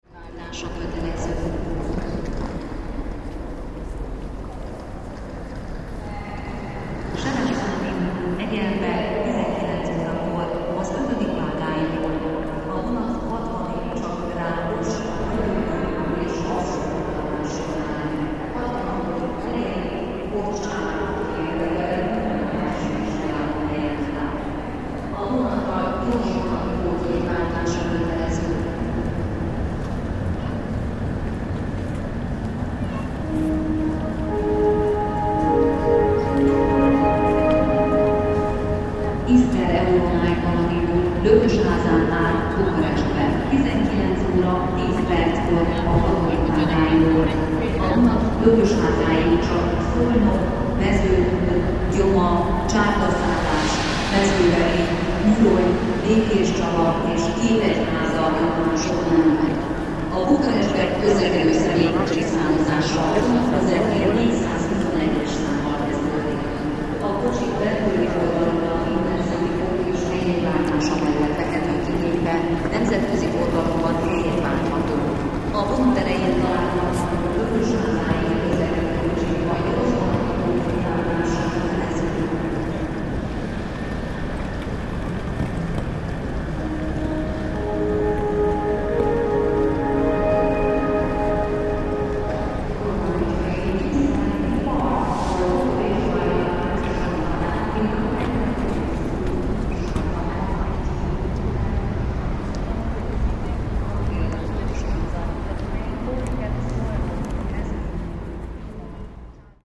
Keleti train station, Budapest